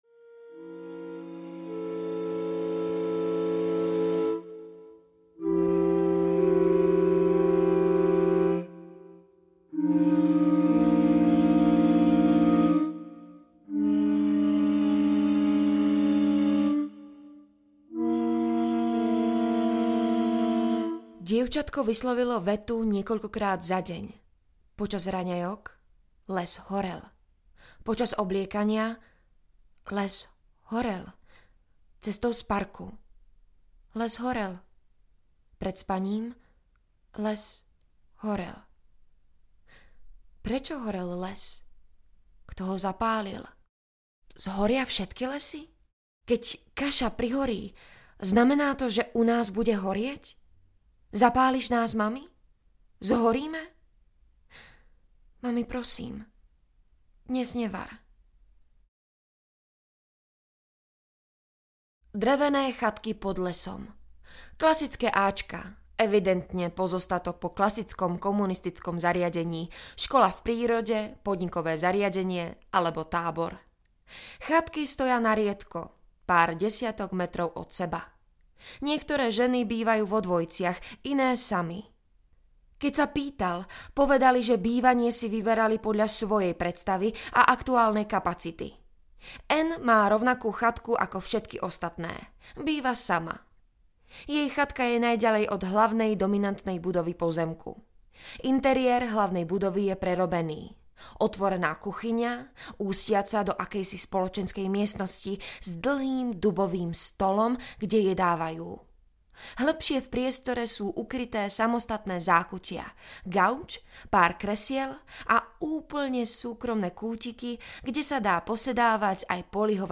Rozhovor s členkou kultu audiokniha
Ukázka z knihy